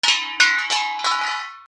/ M｜他分類 / L01 ｜小道具 / 金属
金具を落とす ピッチ下 『カラン』